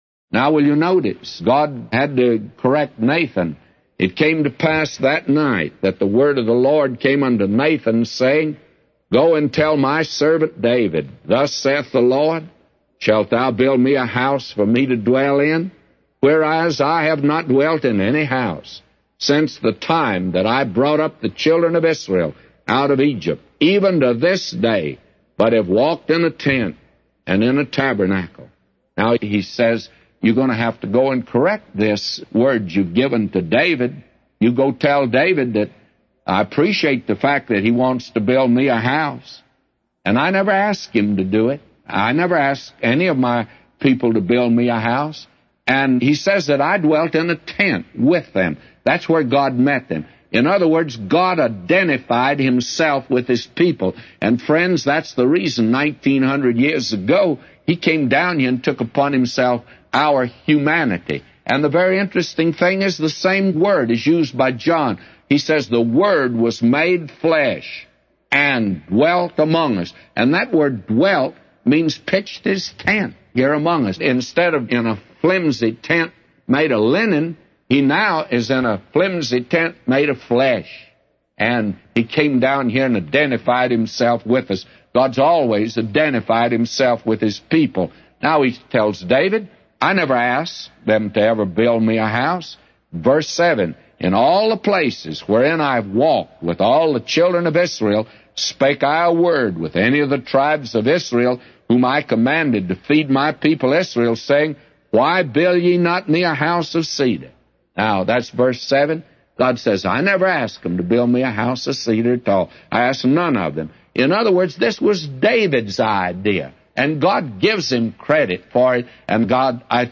A Commentary By J Vernon MCgee For 2 Samuel 7:4-999